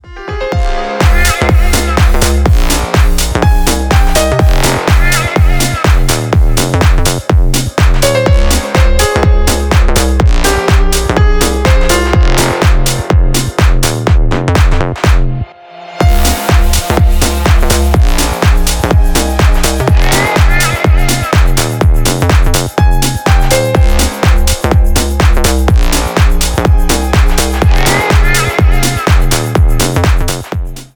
Танцевальные
клубные # без слов